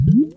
lavapop.ogg